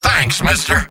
Robot-filtered lines from MvM. This is an audio clip from the game Team Fortress 2 .
{{AudioTF2}} Category:Engineer Robot audio responses You cannot overwrite this file.